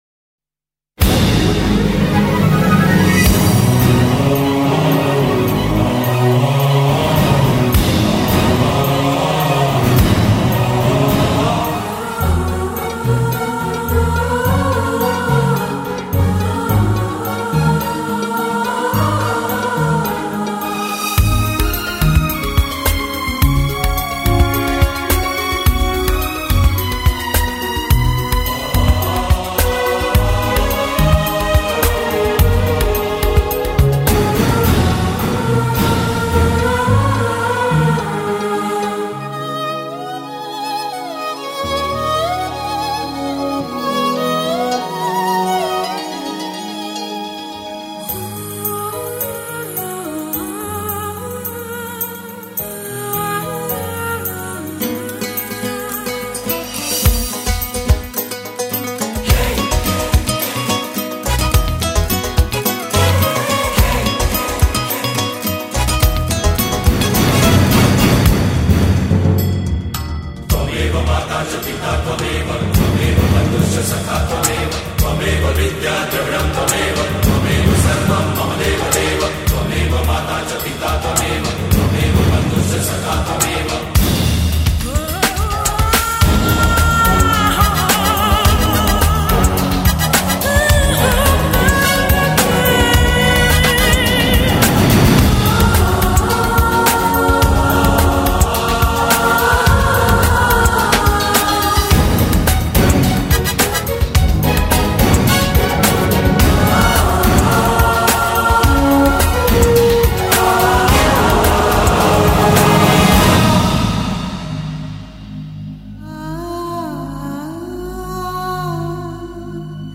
[Artist: Instrumental ]